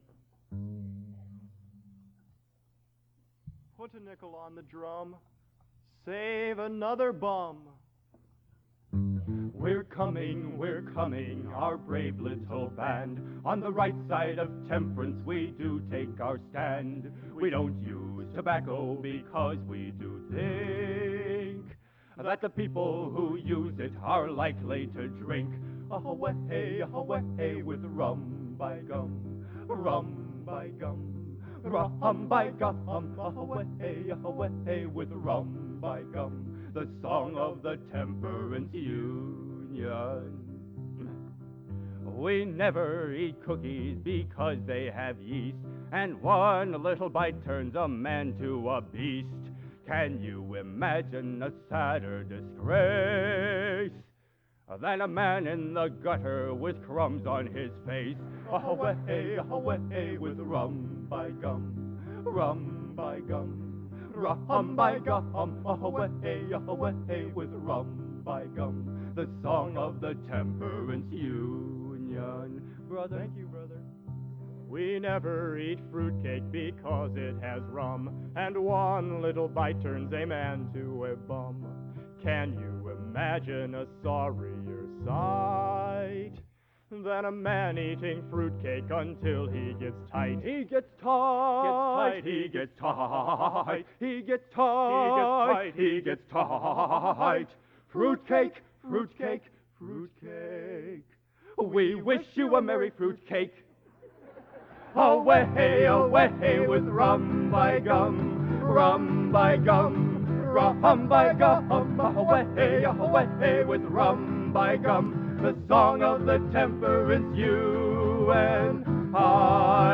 Genre: | Type: End of Season |Featuring Hall of Famer |Specialty